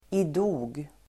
Ladda ner uttalet
Uttal: [²'i:dog]